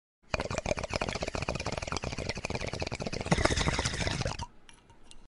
bong.mp3